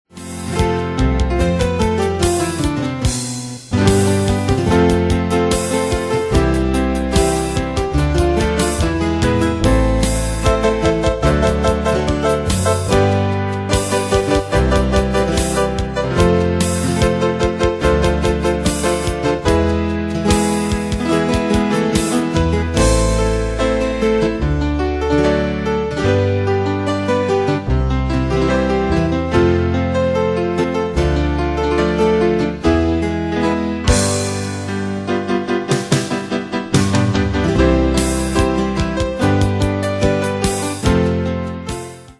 Style: PopAnglo Année/Year: 1970 Tempo: 73 Durée/Time: 3.32
Danse/Dance: Folk Cat Id.
Pro Backing Tracks